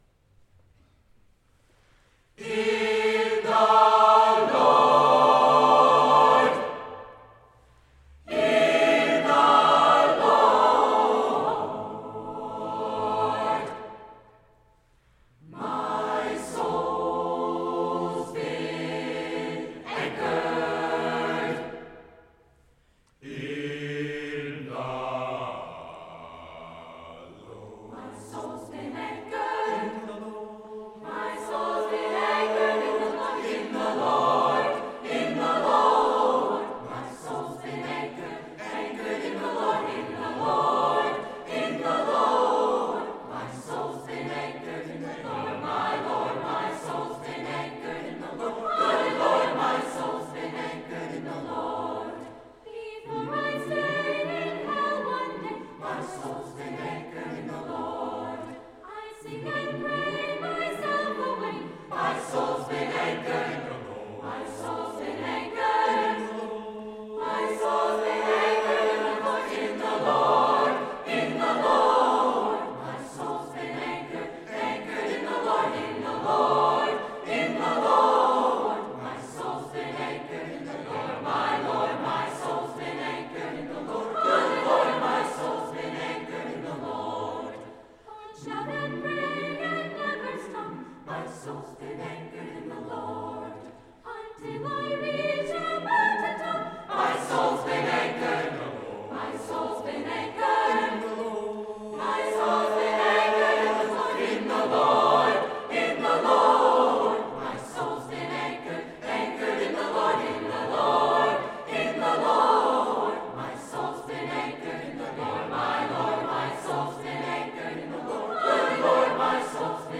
Brookline High School Camerata
Sunday, March 18, 2012 • United Parish, Brookline, MA